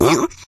Oink1.wav